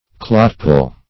clotpoll - definition of clotpoll - synonyms, pronunciation, spelling from Free Dictionary Search Result for " clotpoll" : The Collaborative International Dictionary of English v.0.48: Clotpoll \Clot"poll`\, n. See Clodpoll .